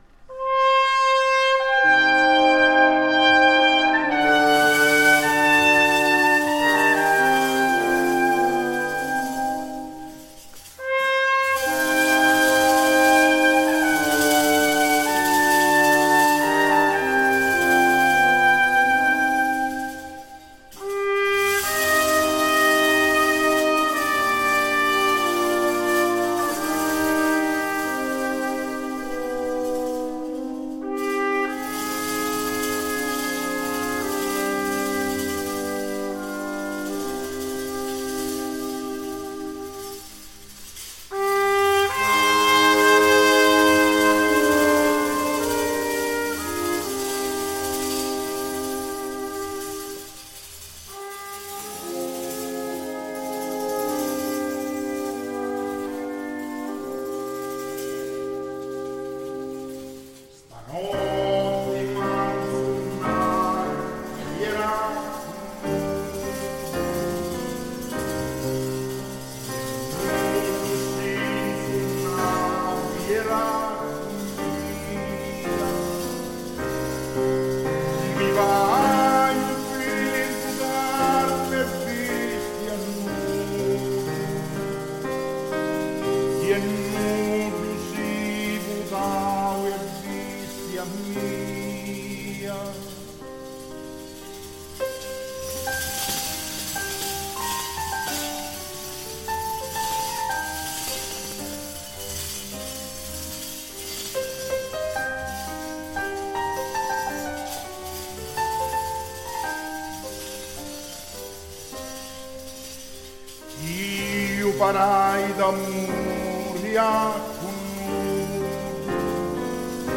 Genre: Klassiek.